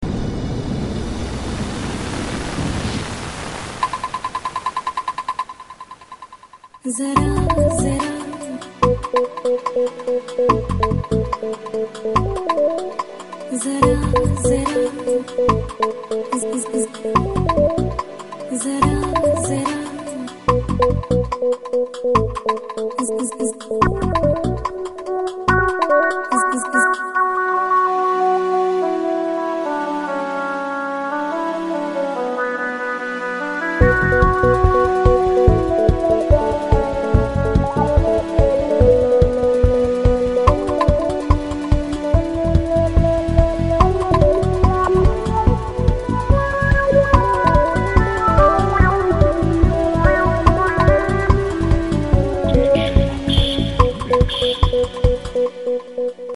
dance/electronic
Breaks & beats